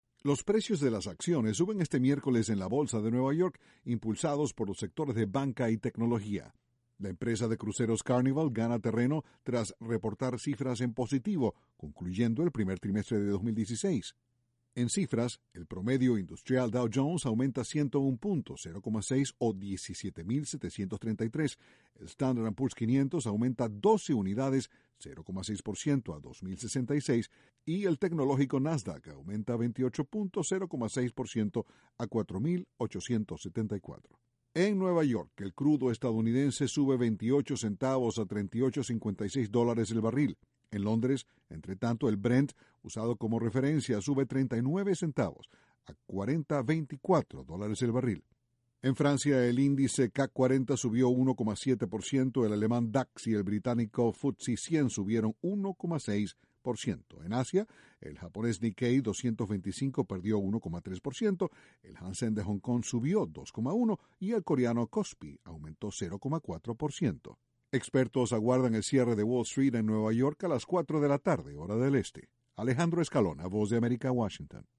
Sube la Bolsa de Nueva York. Desde la Voz de América, Washington